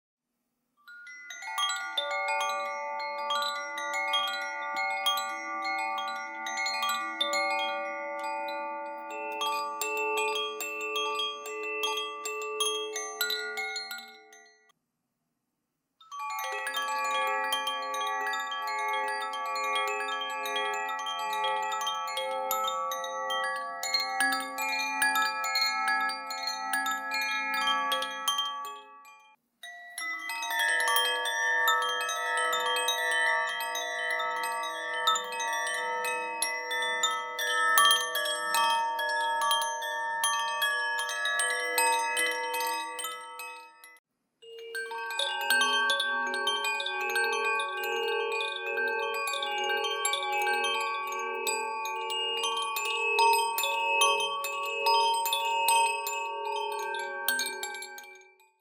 The Meinl Sonic Energy Cosmic Bamboo Chimes produce soft resonant tones that fill your space with serene sound.
Inside is a pendulum and a group of metal tines tuned to specific pitches. Easily modify the tone by switching between the two pendulums: acrylic (hard) and wood (soft).